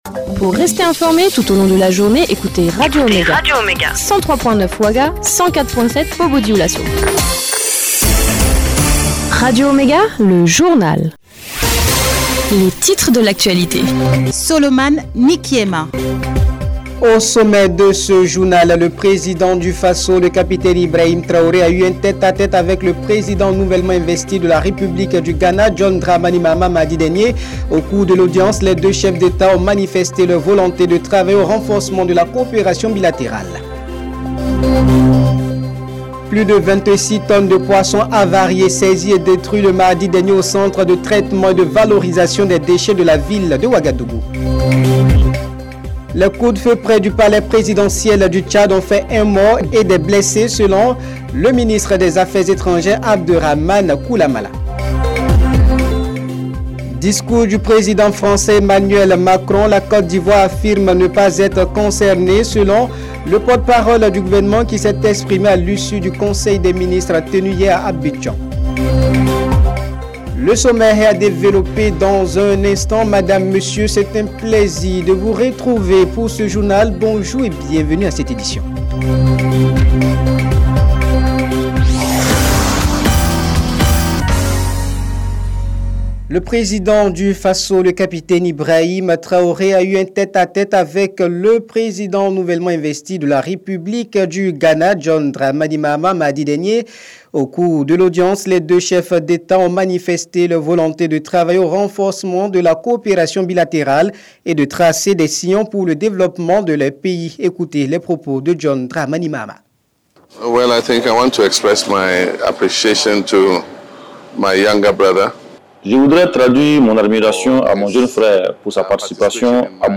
Le journal de 12h15 du jeudi 9 janvier 2024